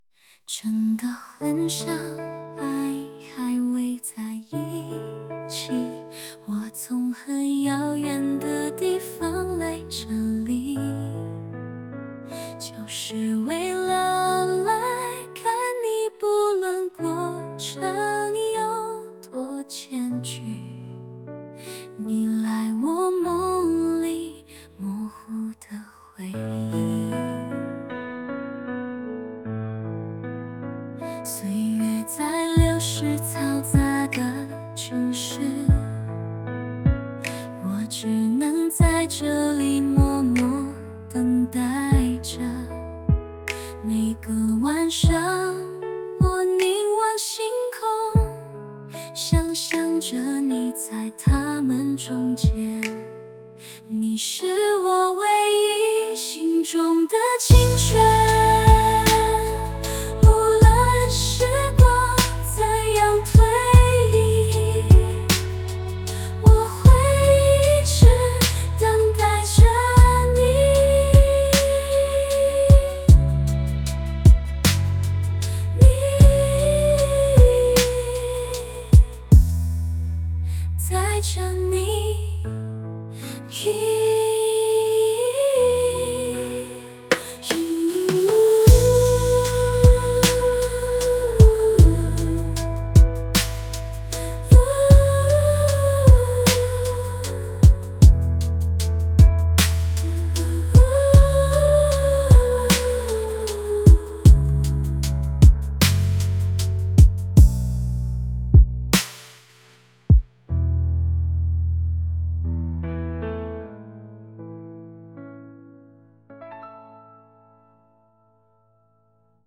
中文歌不设置自定义Mode时，默认好像流行歌，女声，有点像阿桑、梁永琪、邓紫棋之类女歌手的混合体，初一听有时候会想到《一直很安静》这样的感觉。